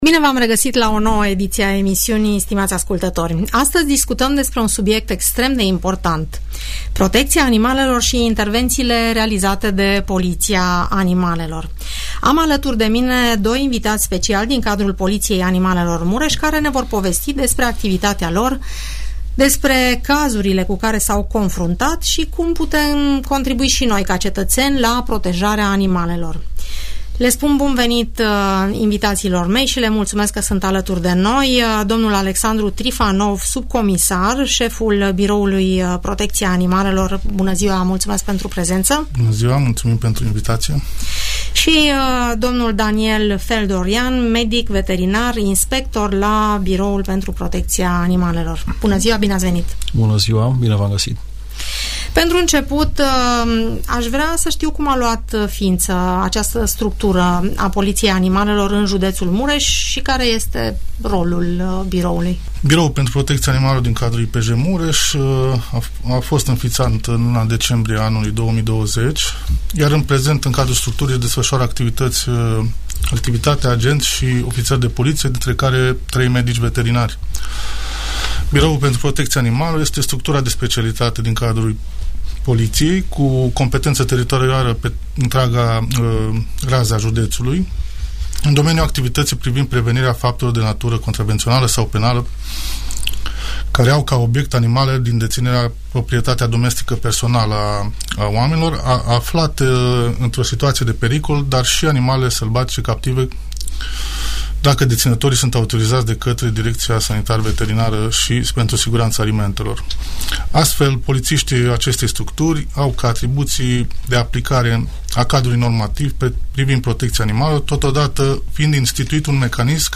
Ce riscă cei care nu-și microciprează, sterilizează sau îngrijesc câinii? Ascultă "Părerea ta" la Radio Tg Mureș, moderată de